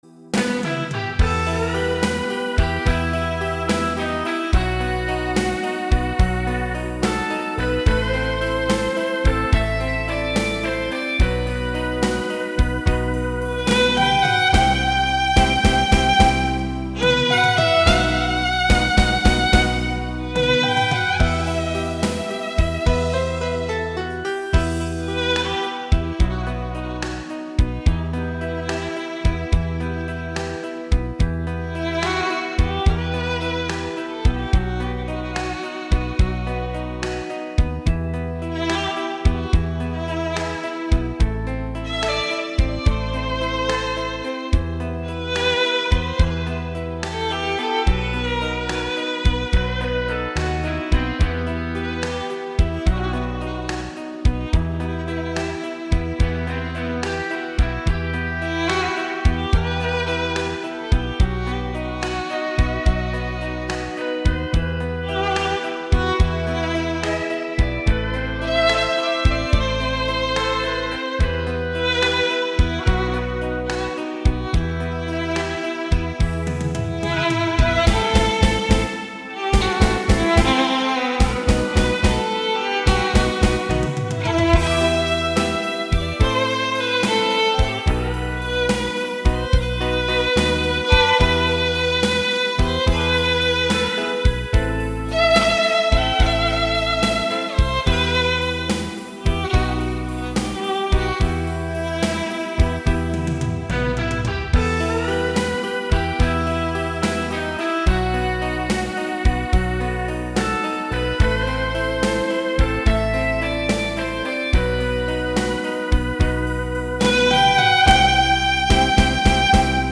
바이올린